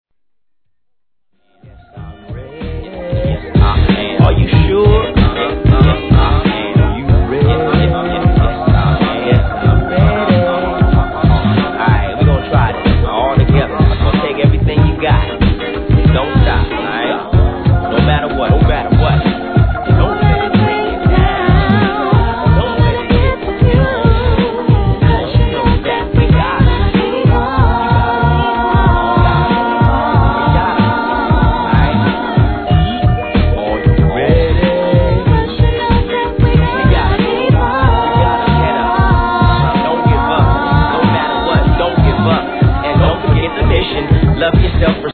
HIP HOP/R&B
ネタの爽やかなグルーブ感溢れる一枚！